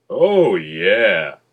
oh_yeah_wav_cut.ogg